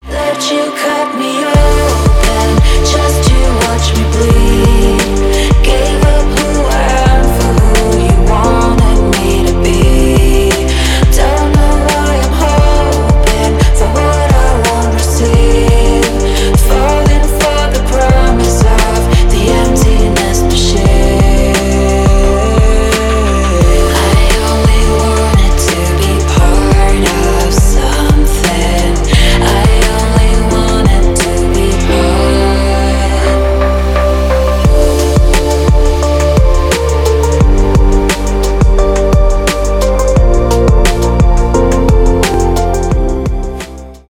cover , танцевальные , мелодичные , chillout